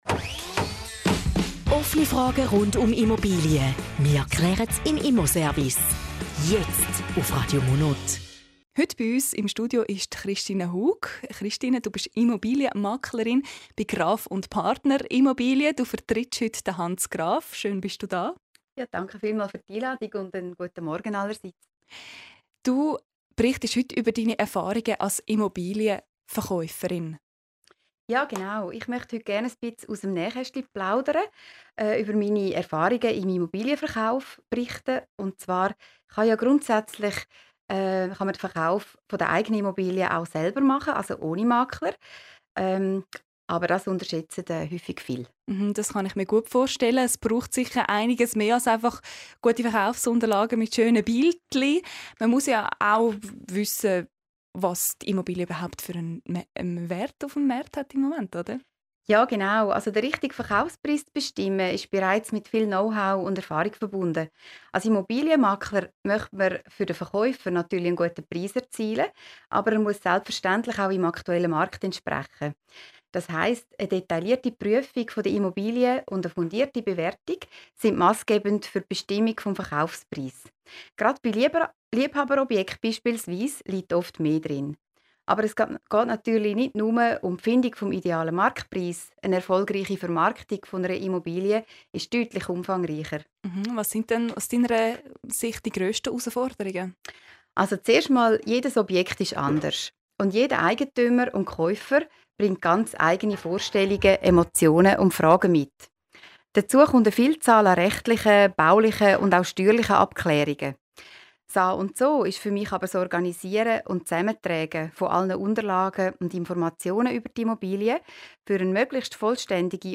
Zusammenfassung des Interviews zum Thema "Einblick in die Immobilienvermarktung":